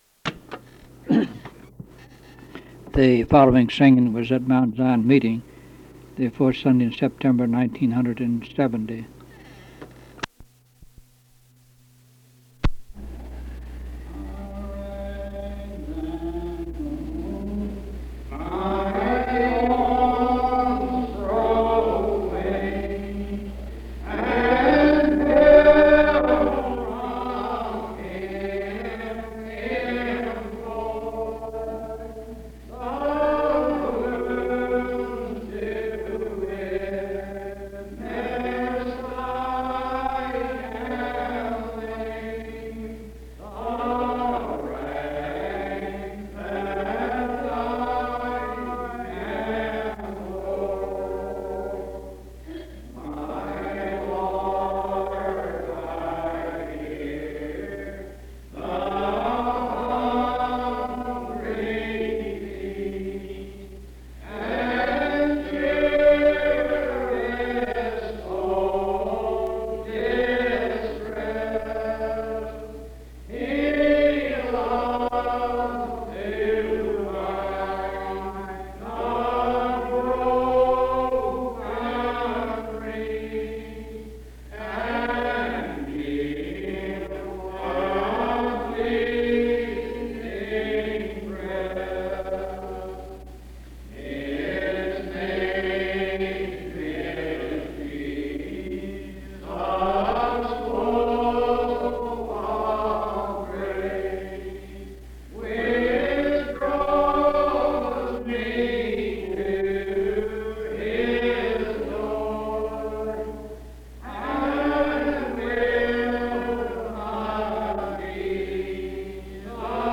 Singing
Hymns Primitive Baptists
Aldie (Va.) Loudoun County (Va.)